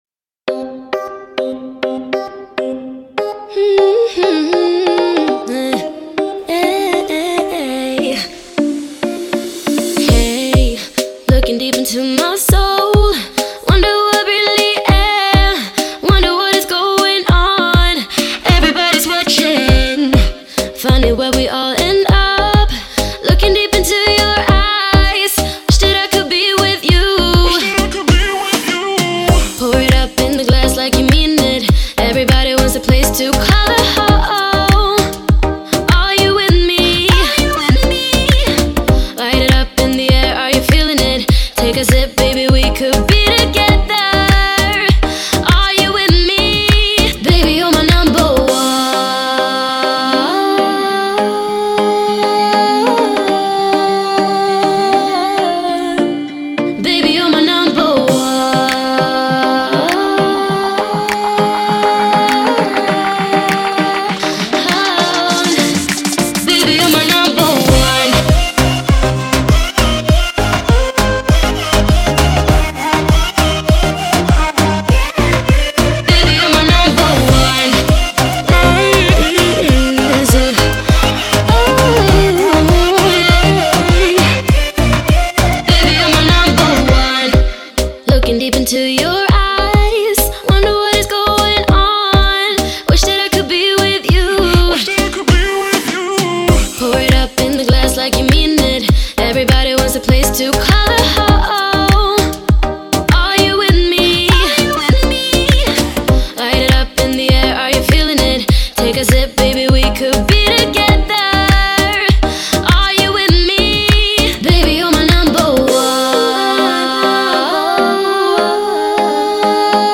это энергичная поп-музыка